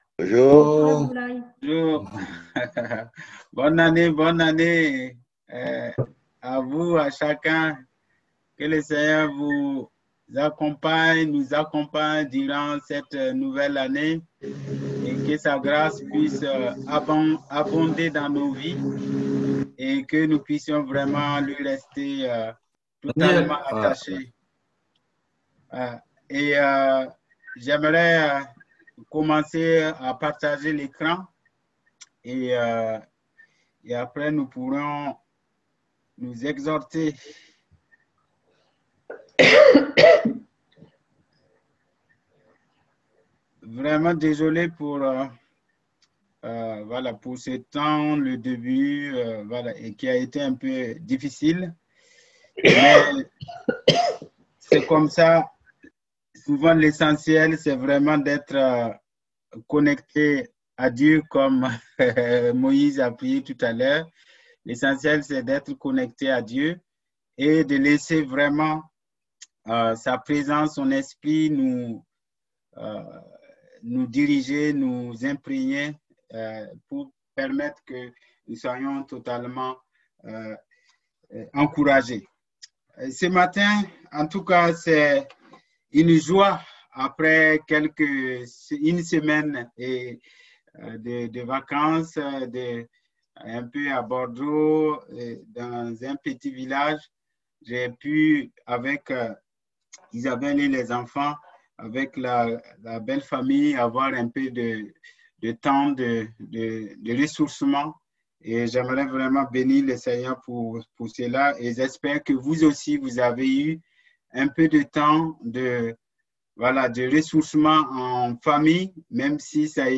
Type De Service: Messages du dimanche